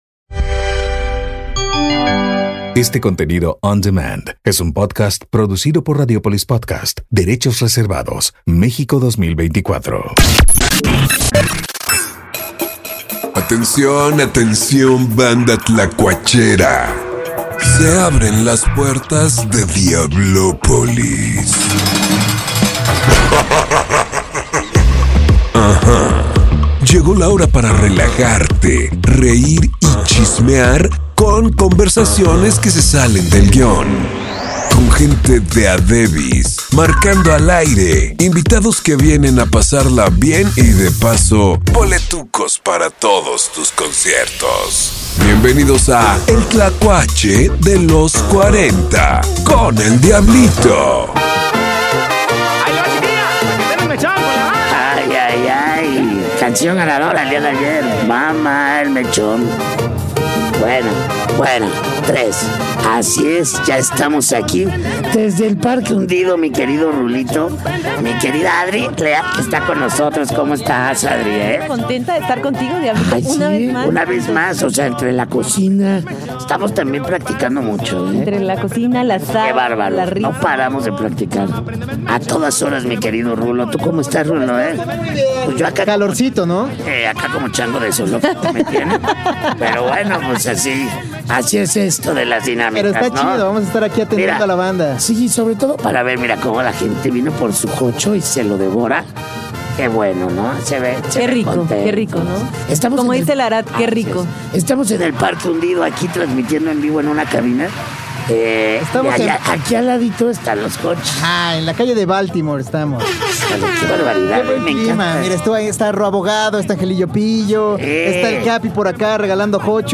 Deja Vu Retro Show tocando en vivo…